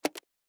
pgs/Assets/Audio/Sci-Fi Sounds/Mechanical/Device Toggle 04.wav at 7452e70b8c5ad2f7daae623e1a952eb18c9caab4
Device Toggle 04.wav